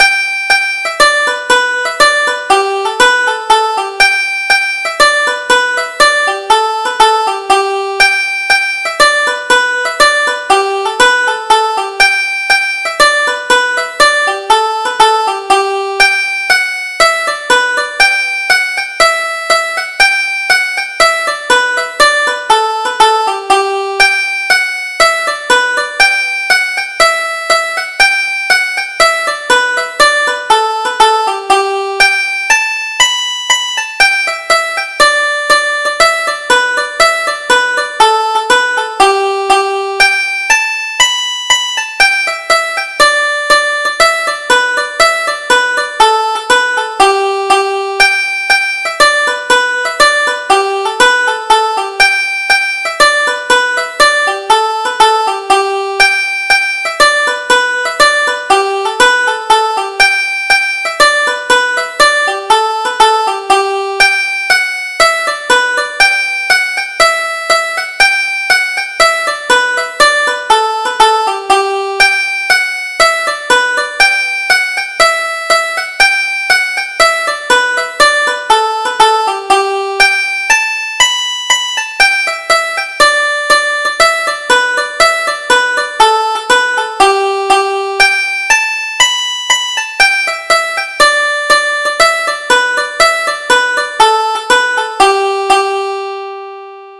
Irish Traditional Polkas